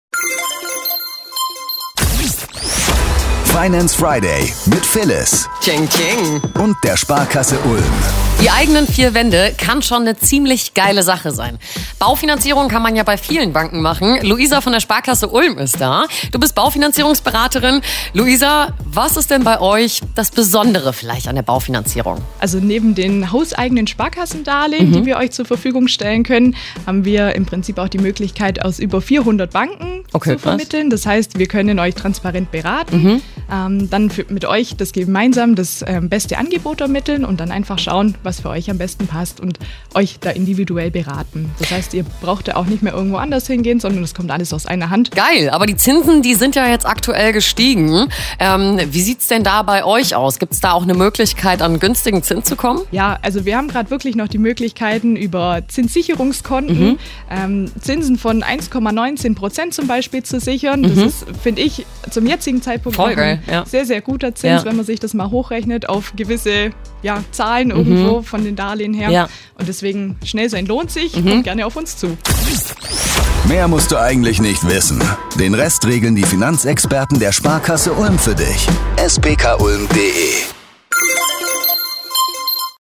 Wöchentlich Finance Friday On Air Content